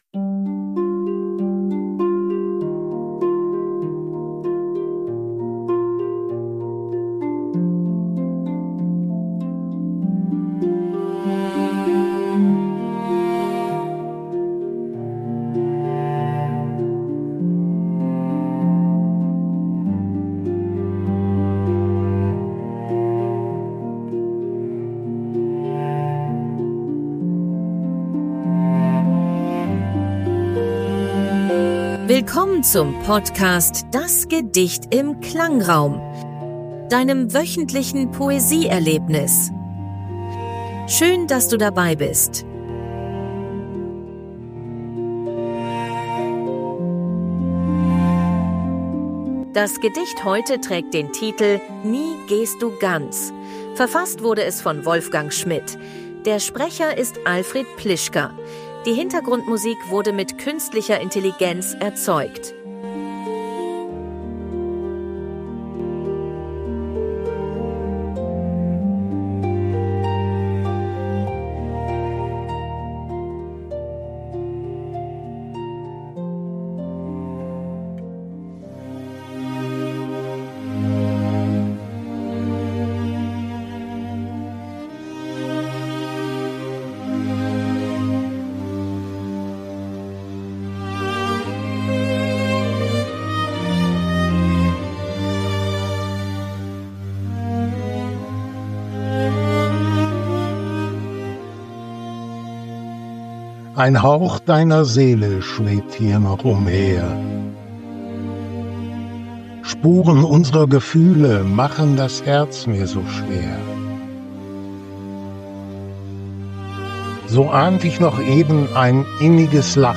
Die Hintergrundmusik wurde
mit Künstlicher Intelligenz erzeugt. 2025 GoHi (Podcast) - Kontakt: